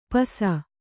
♪ pasa